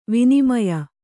♪ vinimaya